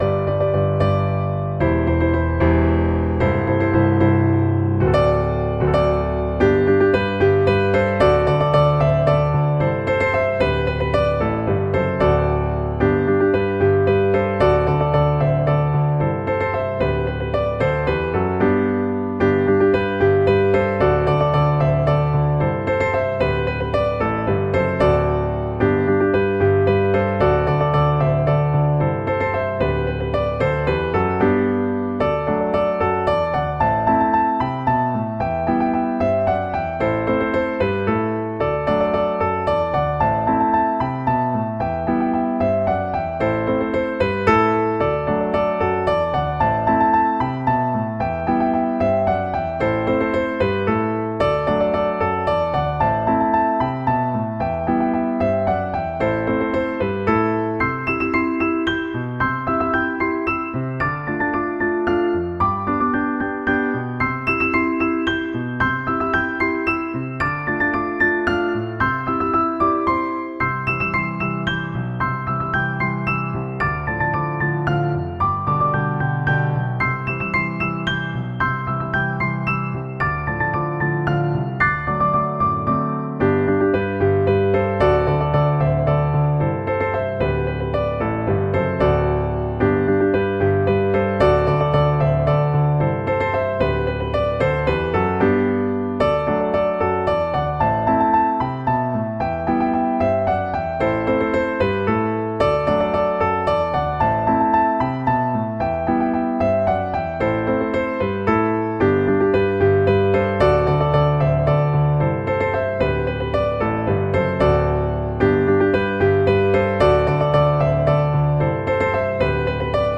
Most of them were written and recorded in just a few hours, and they sound like it.
A dance in 6/8:
Please note that it is the computer playing the piano on these, not me.
Dance.mp3